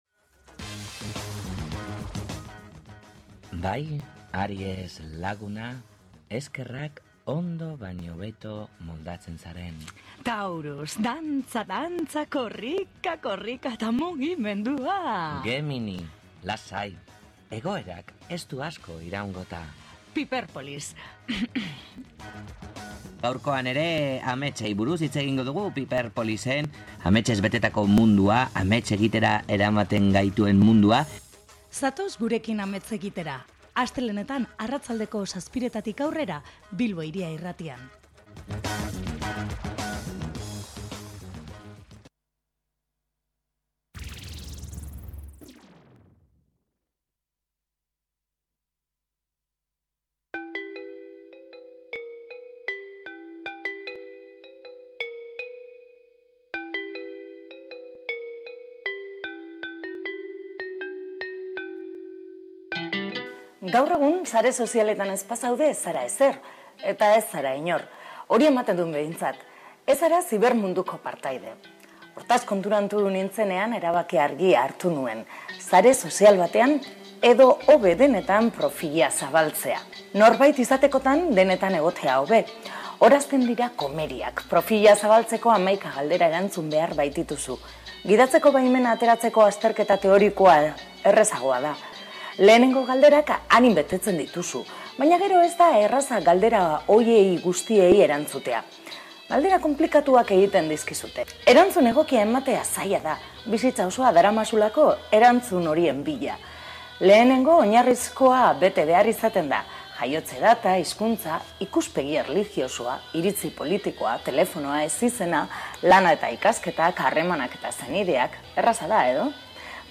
PIPERPOLIS: Ruper Ordorikarekin solasean